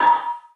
drum free percussion reverb sample stereo sound effect free sound royalty free Music